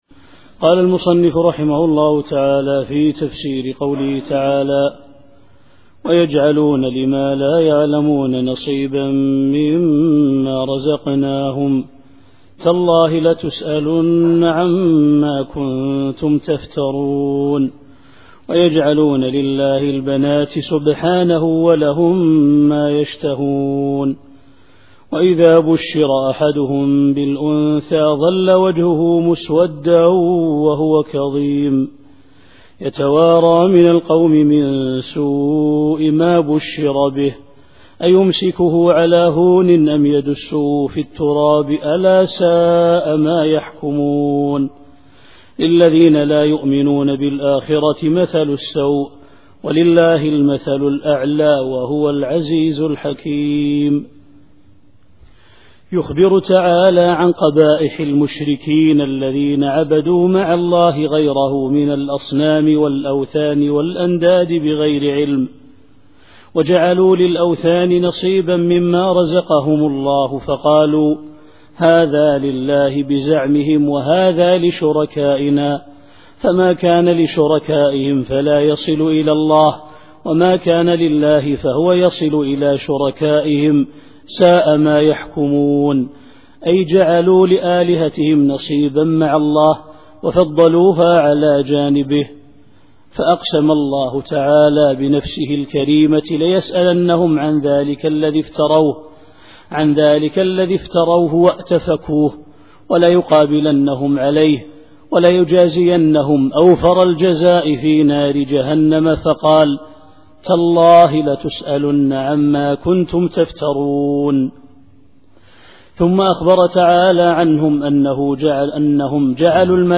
التفسير الصوتي [النحل / 60]